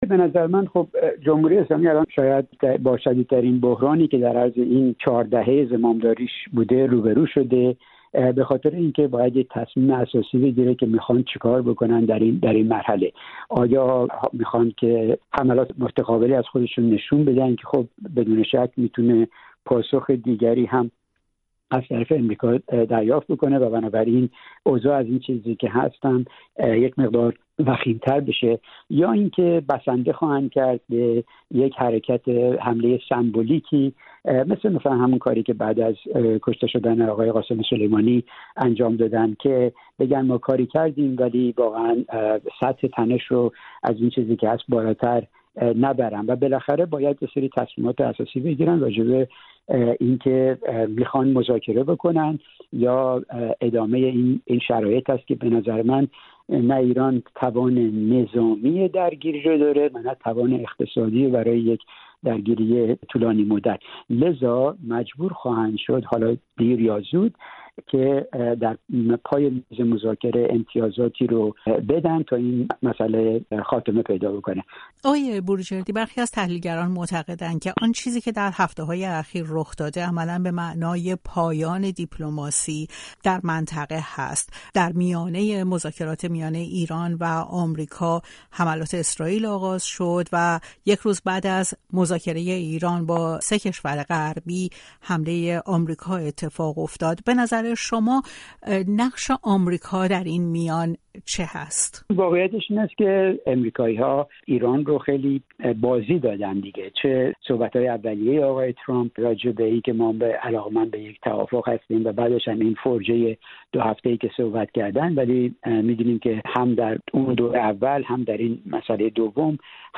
در گفت و گو با رادیو فردا